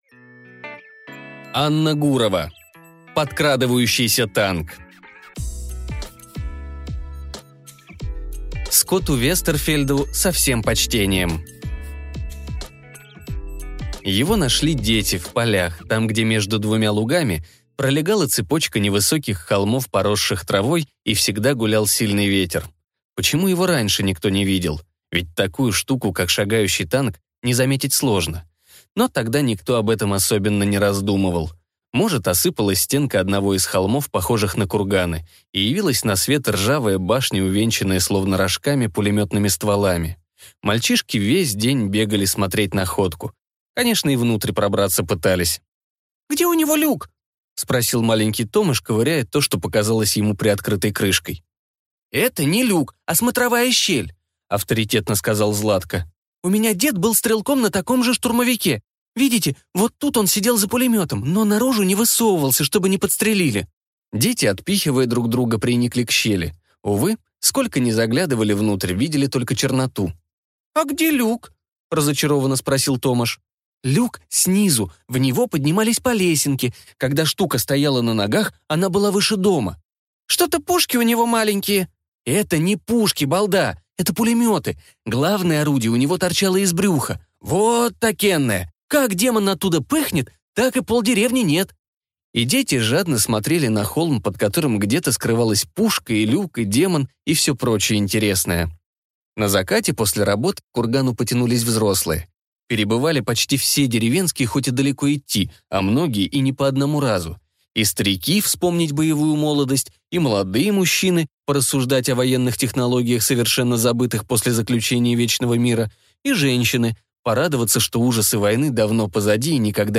Аудиокнига Подкрадывающийся танк | Библиотека аудиокниг